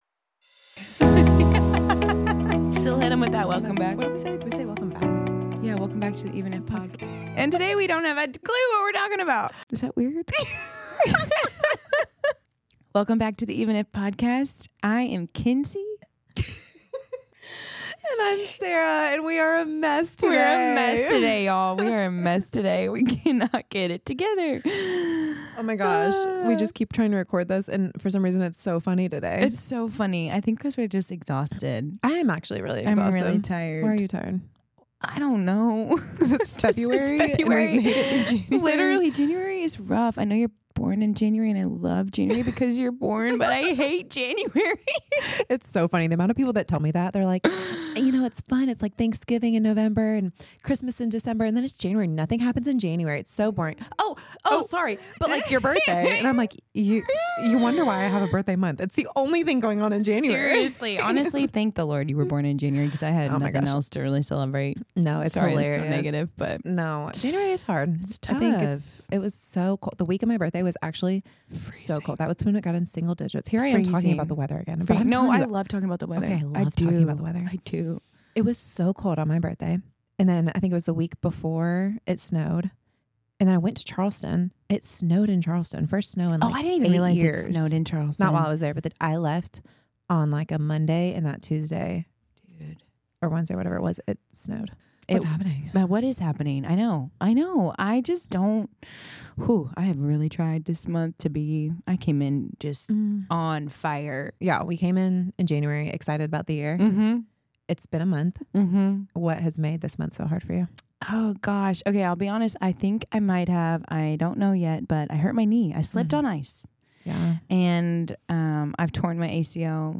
On this week’s episode of The Even If Podcast, it was a full on yip-yap, chit-chat, hang-with-us, convo-over-content kind of recording.